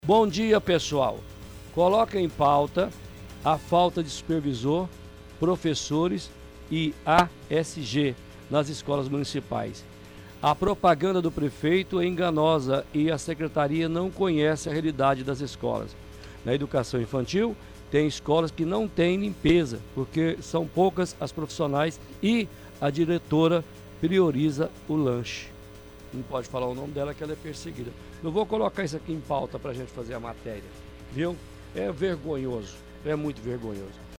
– Ouvinte reclama da falta de profissionais nas escolas municipais.